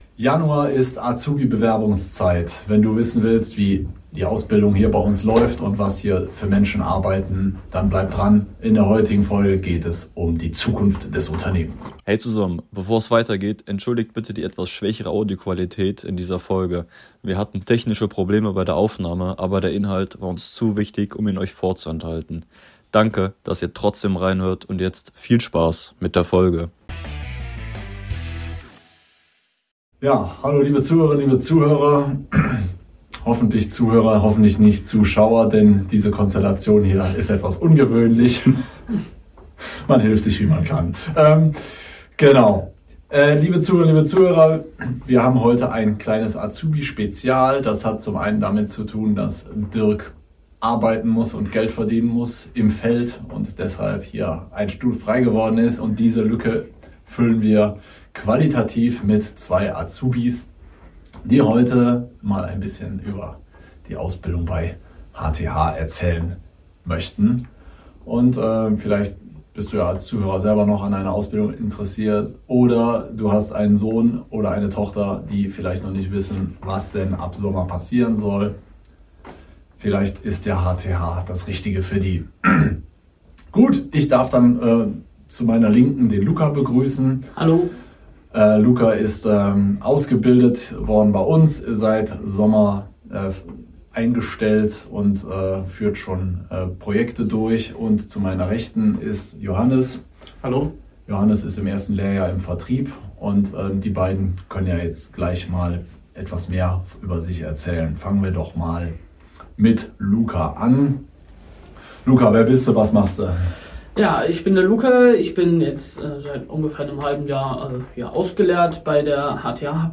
In dieser Folge sprechen wir mit zwei Azubis (Technik & Vertrieb) über ihren Alltag bei HTH: Aufgaben, Projekte, Teamkultur und was du wirklich mitbringen solltest.